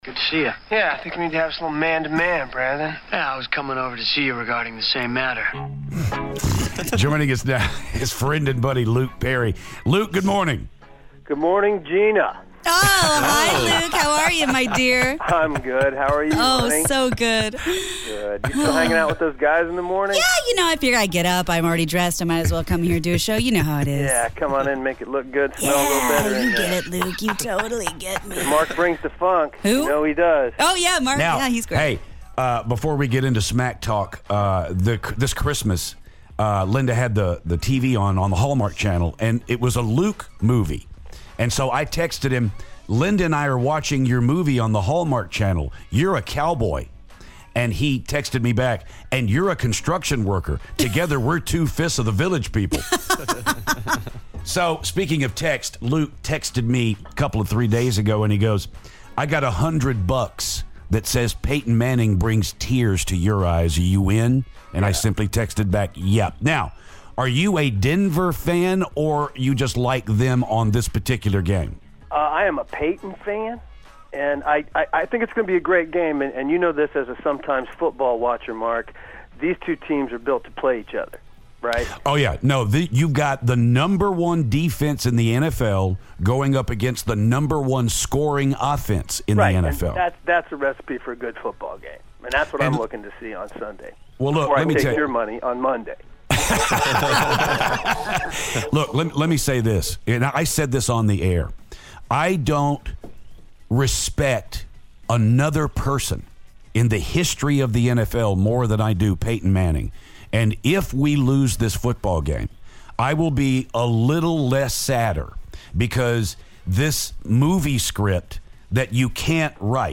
Luke Perry Phoner